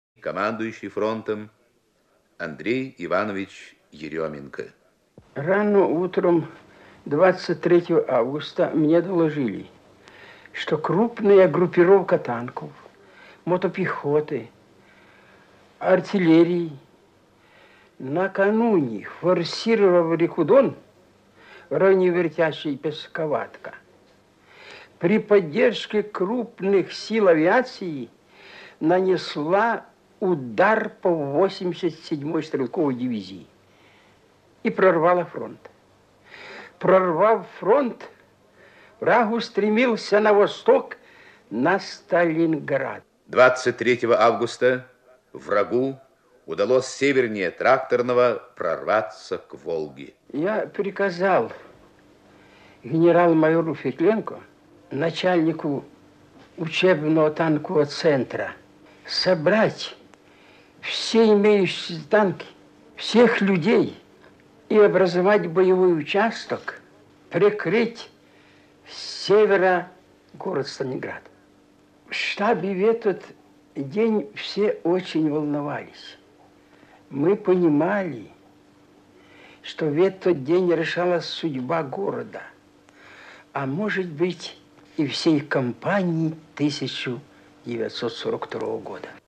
Маршал Андрей Еременко вспоминает о событиях лета 1942 года – начале битвы за Сталинград (архивная запись).